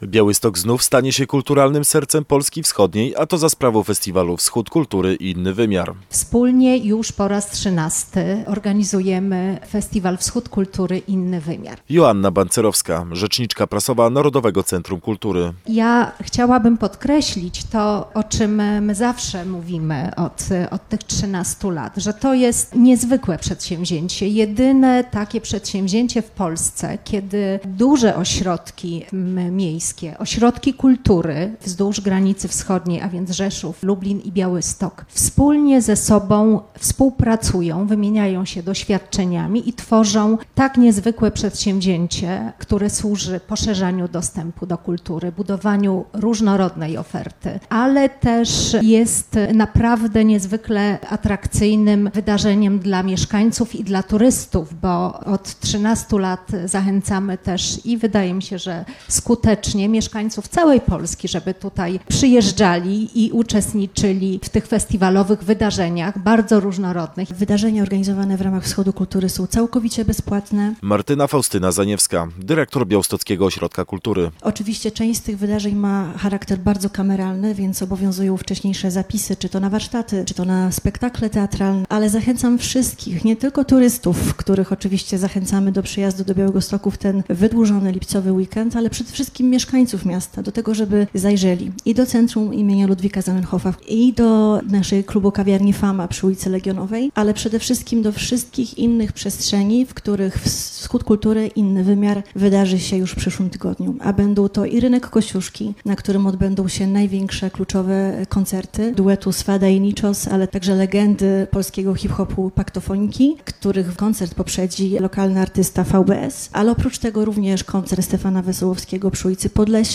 Zapowiedź